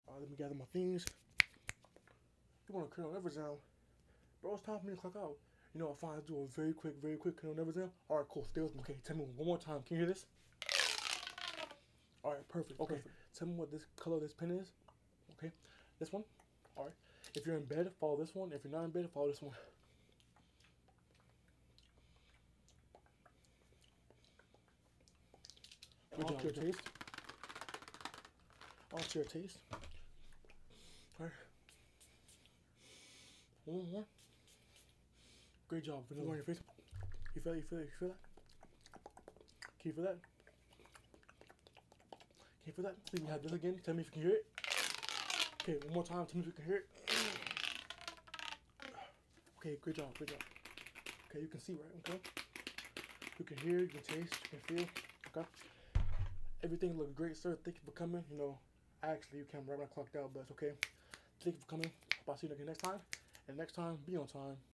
ASMR| Very Fast Cranial Nerve Sound Effects Free Download